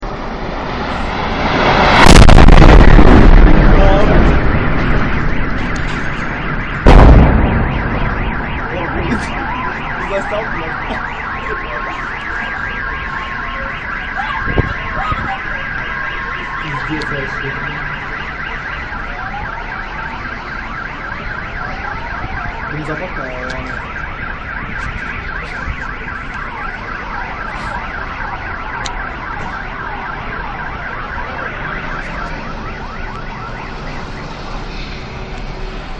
Звуки взрывов разные
Большой взрыв в городе и сигнализации автомобилей
bolshoi-vzryv-v-gorode-i-signalizatsii-avtomobilei.mp3